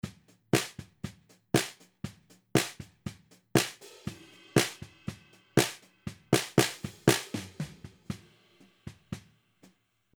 Snare Bottom Mic:
Bottom Snare dry drum sound